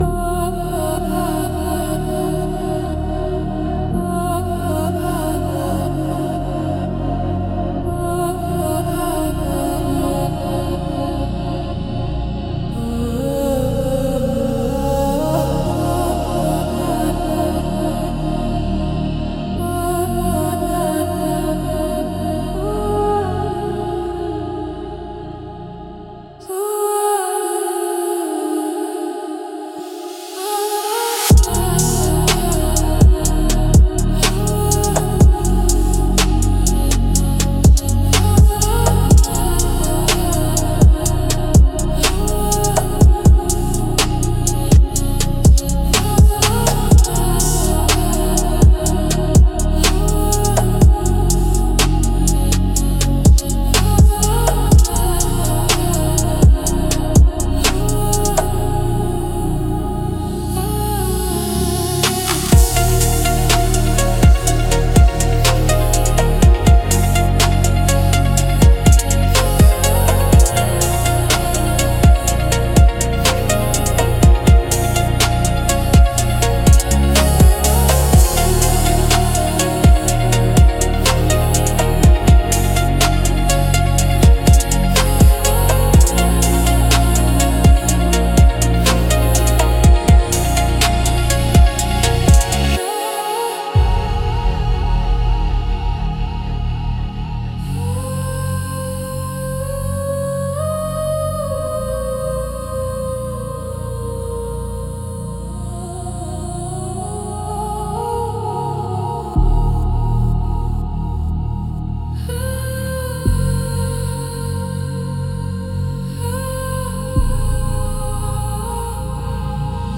Instrumental - Forgotten Languages 3.12